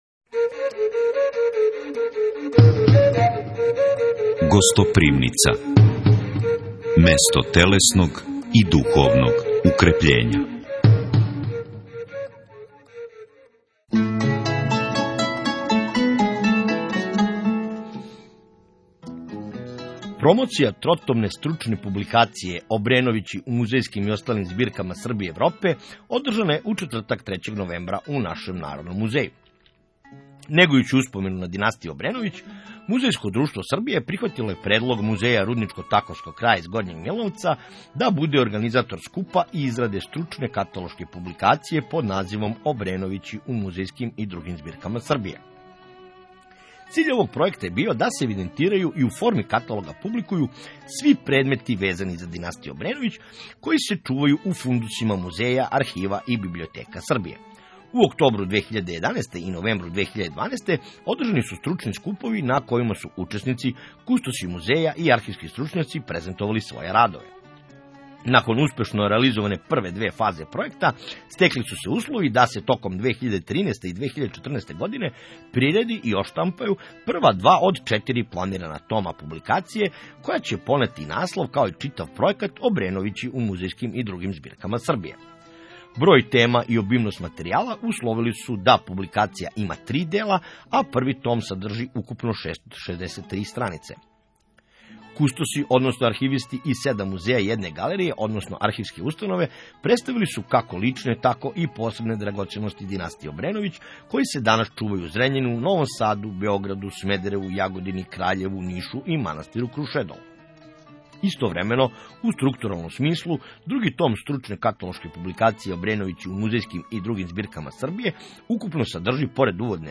У данашњој емисји „Гостопримница” Радија „Златоусти” имаћете прилике да чујете разговоре и изјаве свих релевантних учесника ове промоције као и крагујевачких аутора радова заступљених у овом капиталном зборнику.